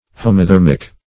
Homothermic \Ho`mo*ther"mic\, Homothermous \Ho`mo*ther"mous\, a.